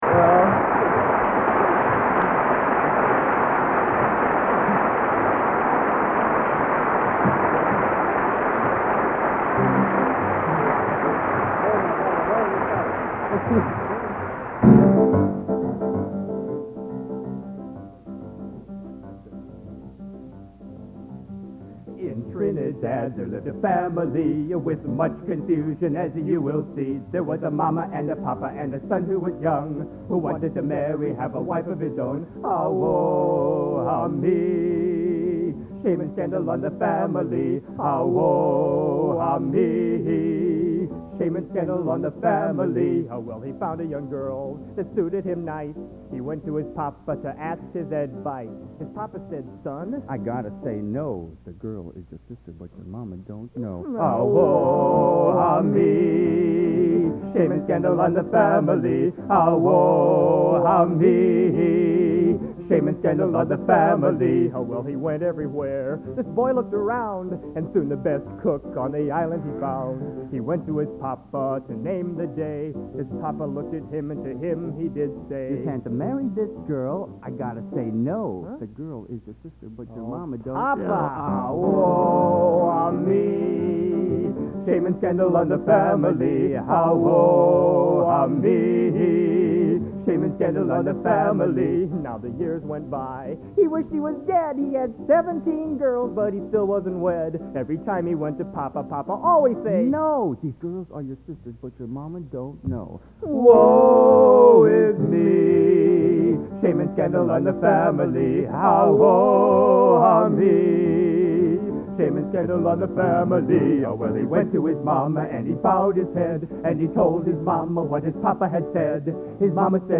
Location: West Lafayette, Indiana
Genre: | Type: End of Season |Featuring Hall of Famer